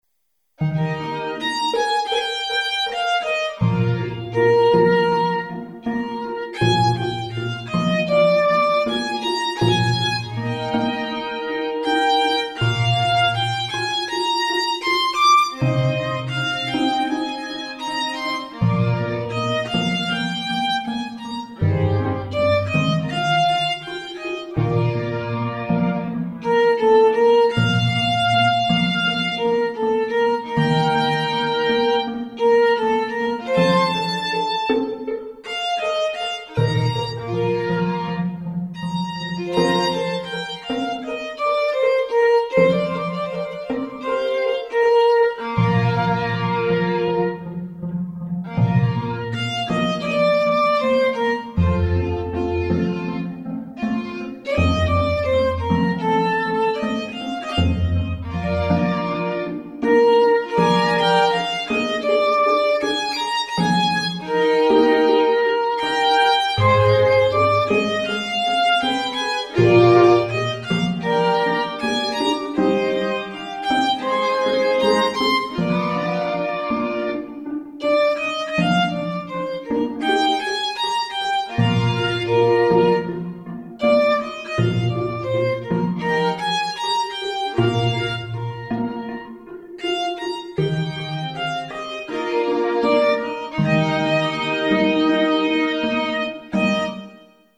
GR-33 (one of my favourite toys...)
Split performance patches (mostly with bass on bottom 2 or 3 strings)
This is a violin (top 4 strings) and orchestra (bottom 4).